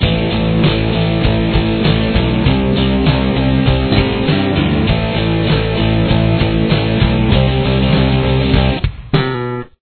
Chorus
Here’s what the guitar and bass sound like together: